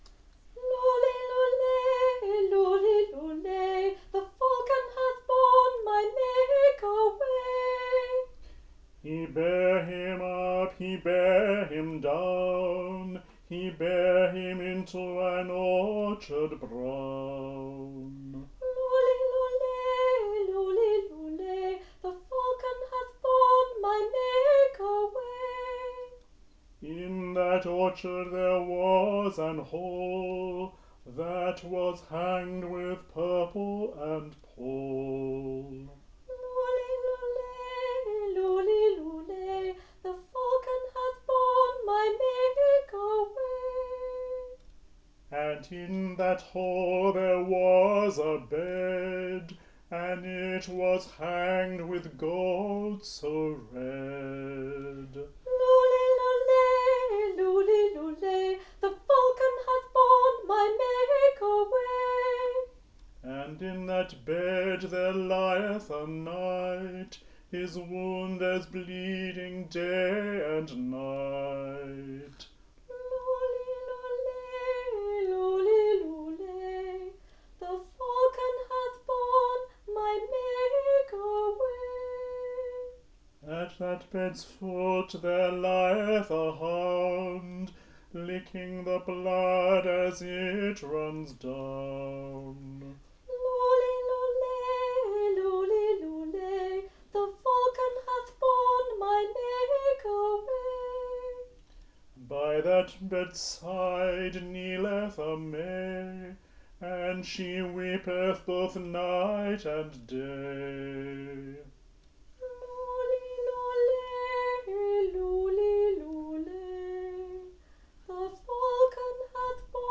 We have also provided two alternative sung versions of the poem for you to listen to:
But traditionally it would have been repeated at the end of each stanza, as it is in the sung version of the ballad.